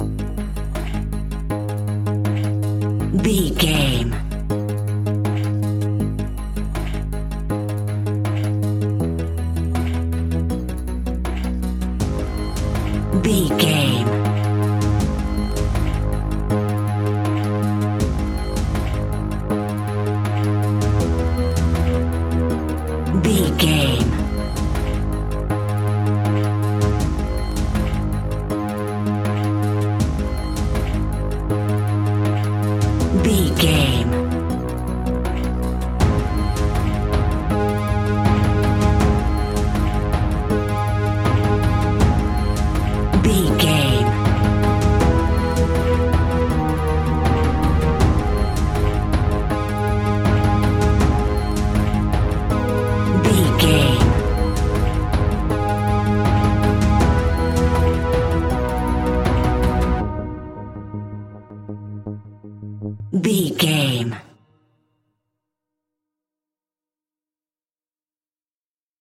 Aeolian/Minor
G#
Slow
ominous
dark
eerie
driving
synthesiser
brass
percussion
horror music